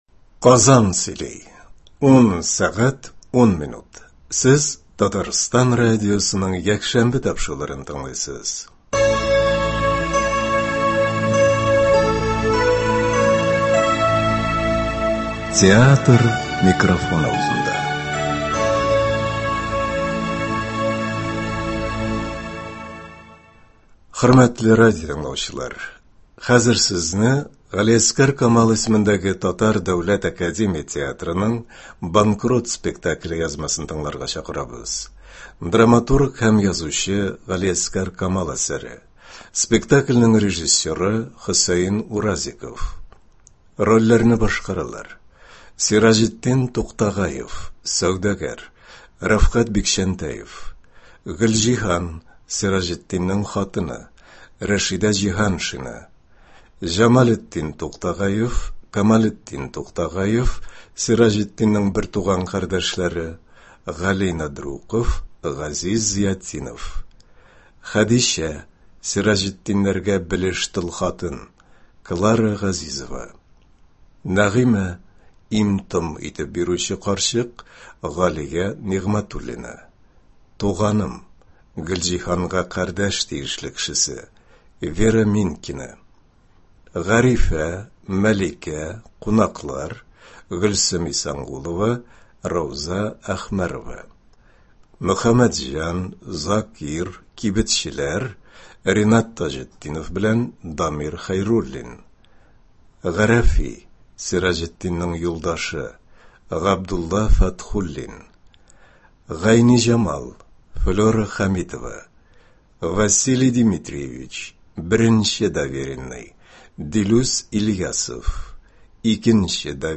Г.Камал ис. ТДАТ спектакленең радиоварианты.
Спектакль магнитофон тасмасына Татарстан радиосы студиясендә 1964 елда язып алынган.